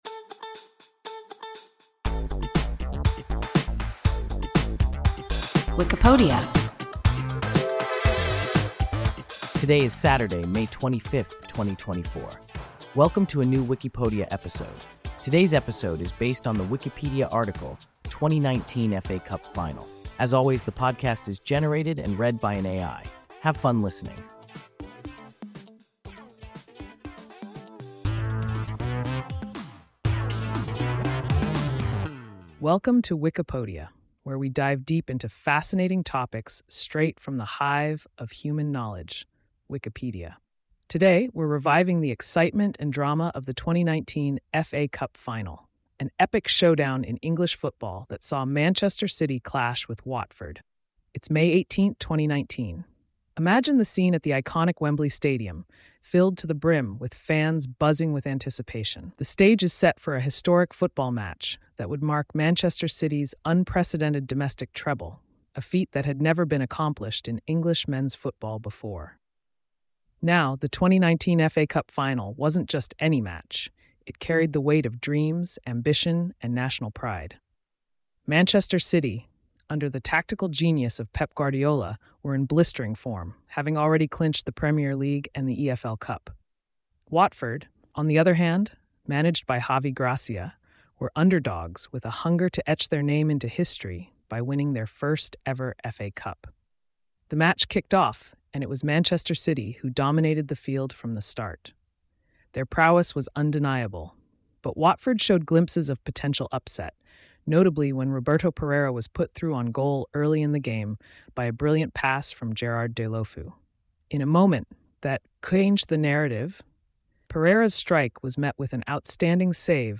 2019 FA Cup final – WIKIPODIA – ein KI Podcast